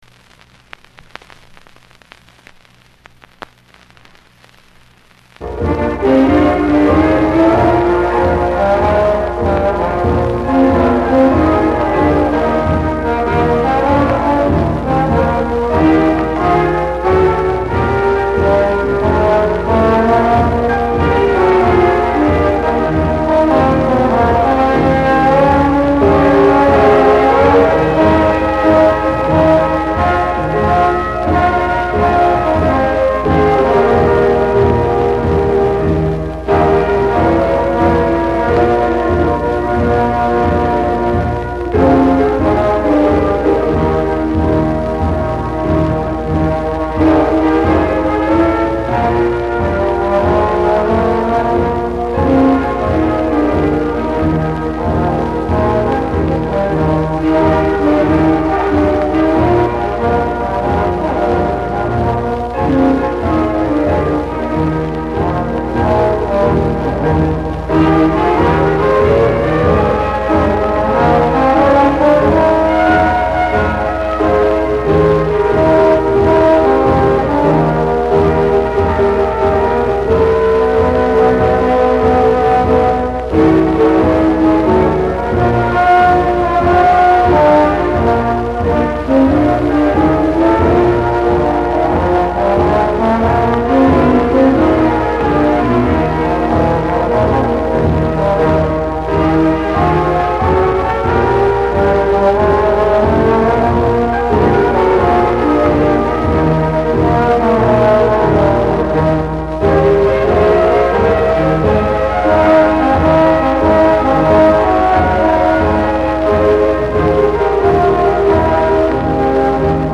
High School Dixieland Band at White Sulphur Springs, Montana
Piano
Trumpet
Clarinet
Sax
Trombone
Drums
Audio is compromised by acoustic echo and record scratches